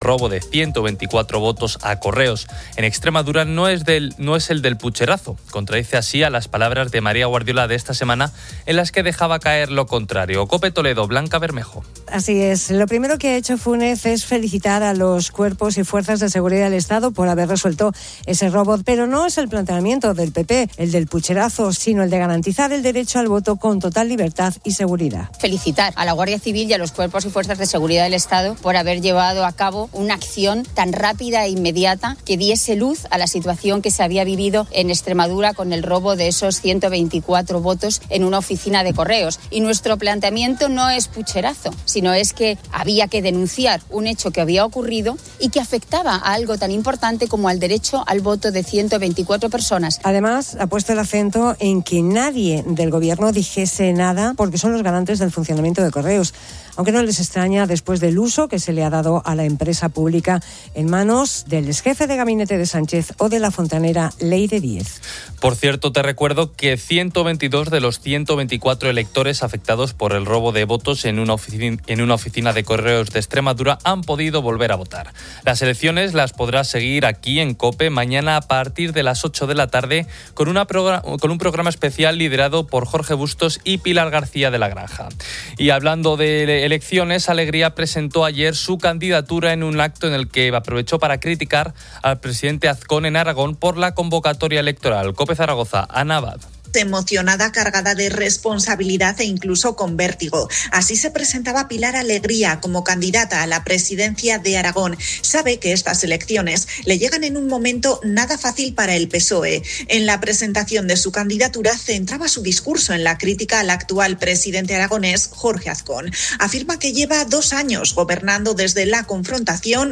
La sección de inteligencia artificial satiriza con audios ficticios
Además, niños pequeños ofrecen sus cómicas versiones del nacimiento de Jesús y los regalos de los Reyes Magos.